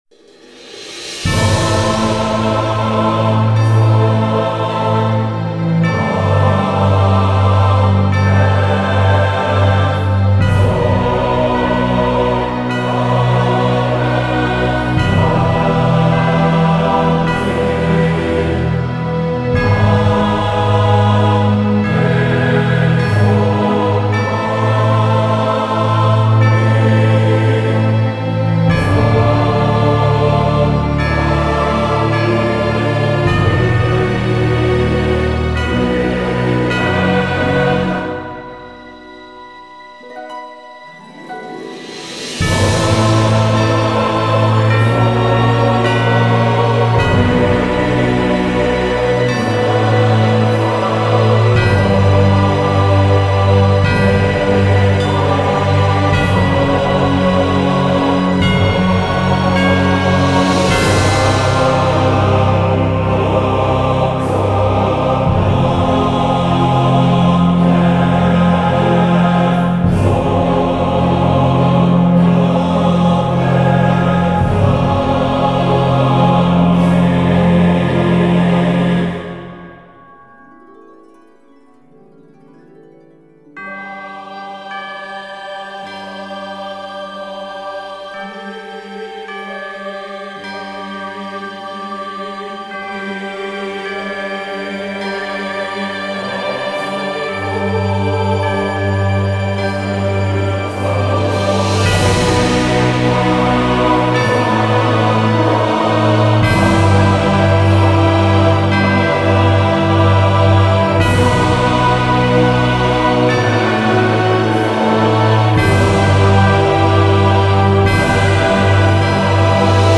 Жанр: Score
Исполнение исключительно инструментальное.